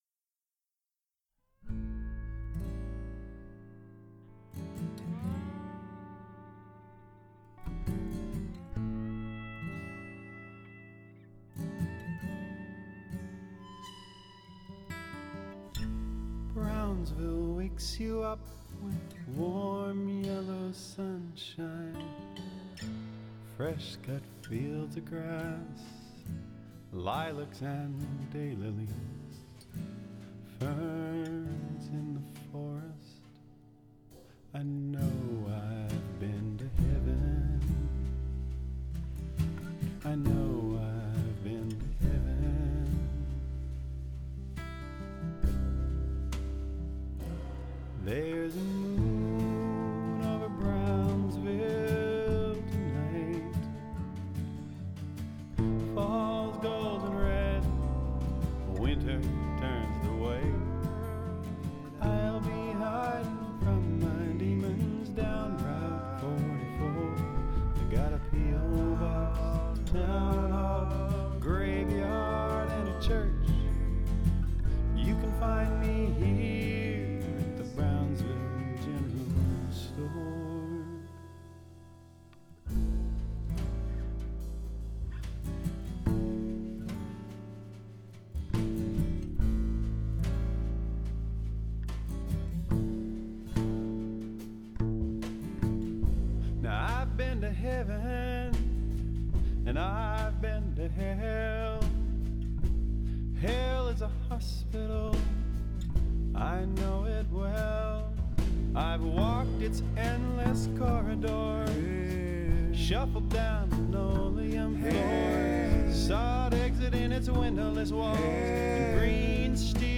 brownsville-choir-A3.mp3